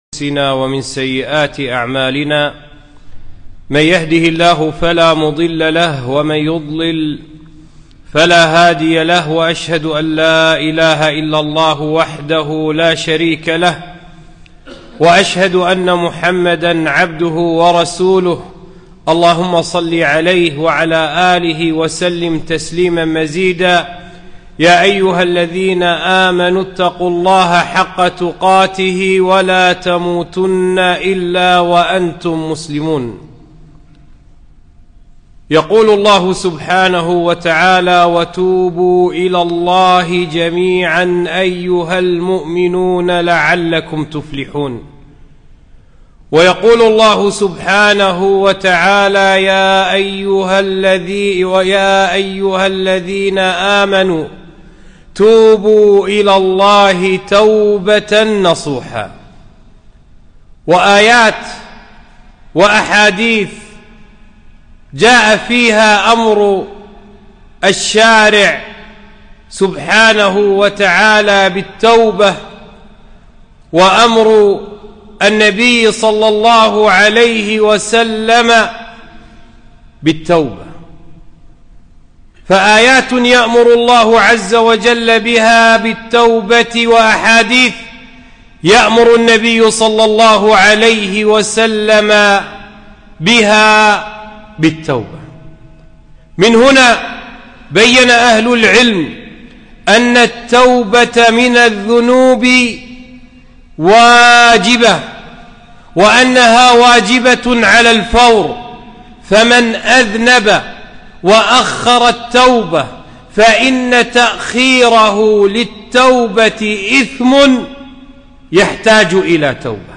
خطبة - وتوبوا إلى الله جميعا